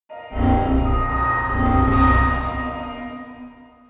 Windows XP error why
windows-xp-error-why.mp3